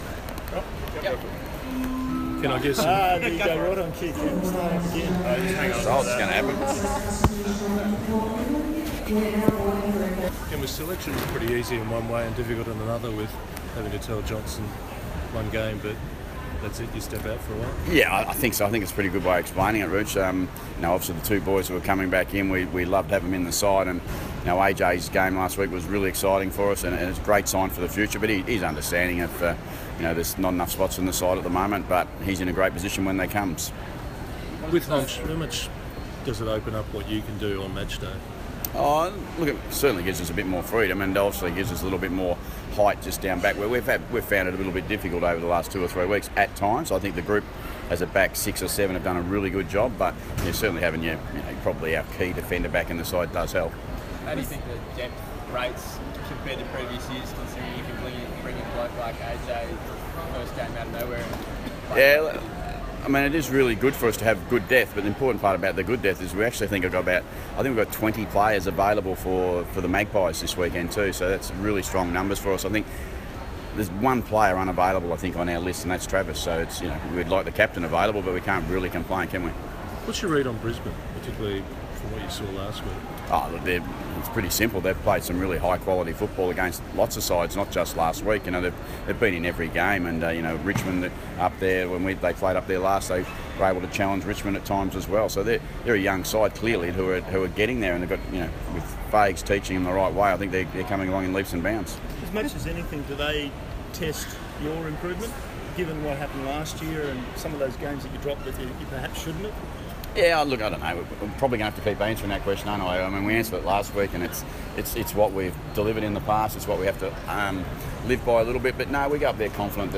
Ken Hinkley press conference - 28 April 2017